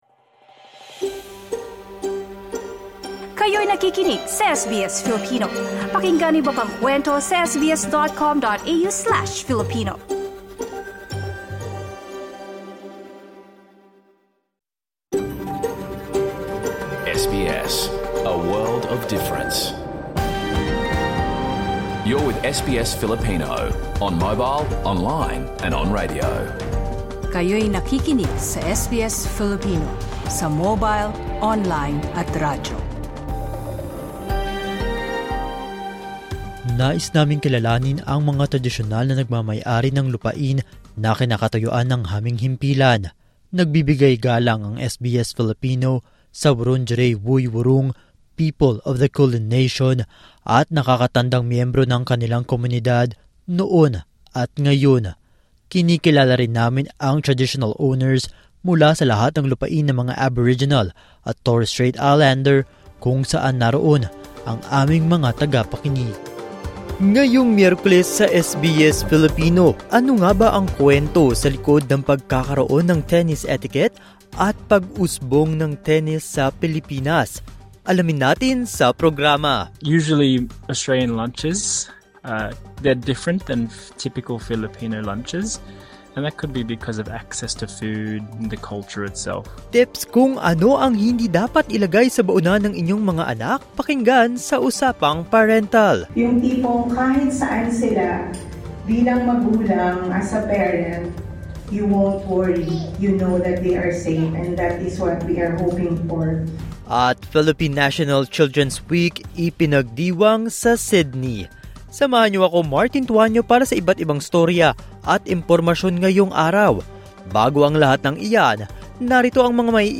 SBS Filipino Radio Program, Wednesday 28 January 2026